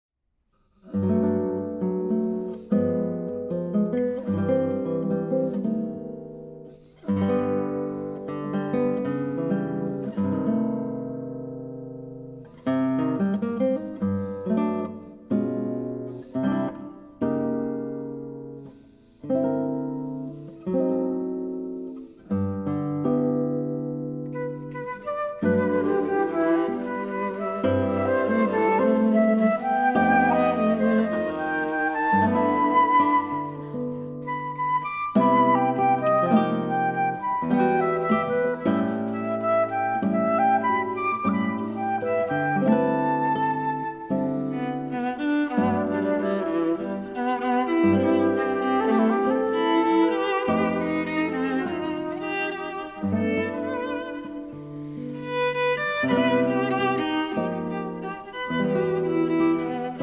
flute, viola and guitar